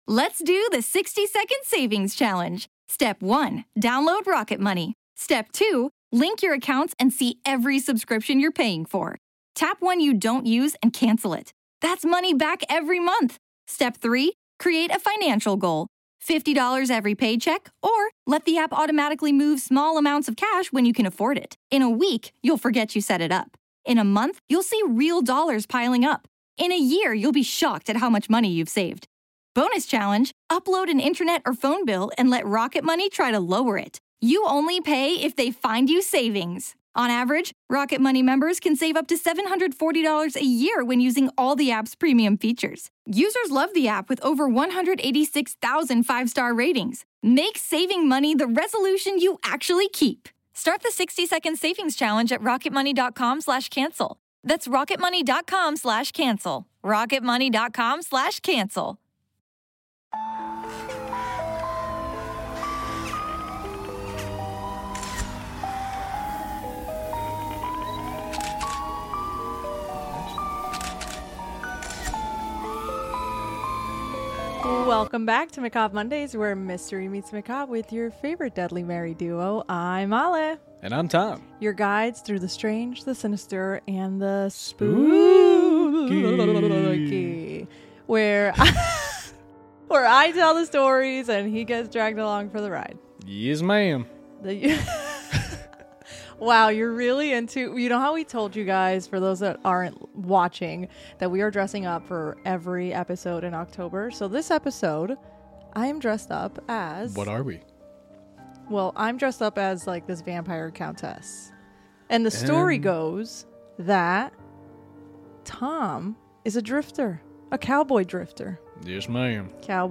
Macabre Mondays is a true crime and paranormal podcast